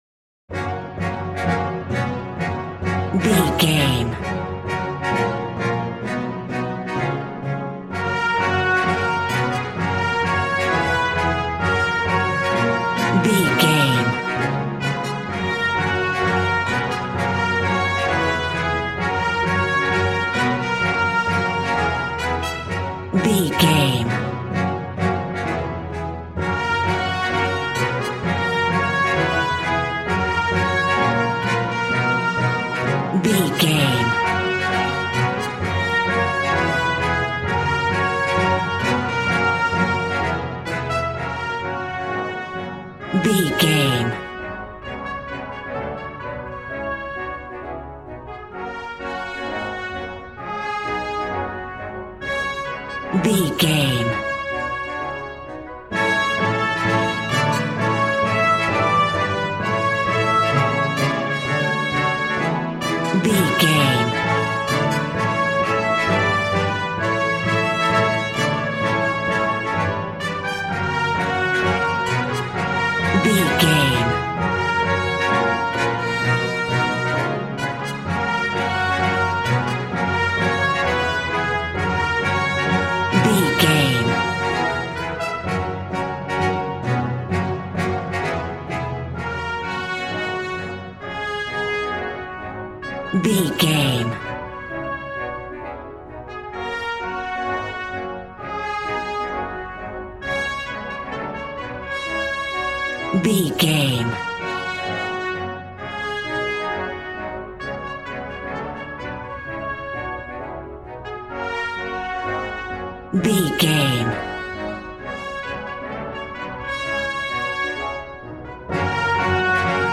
Ionian/Major
G♭
percussion
violin
cello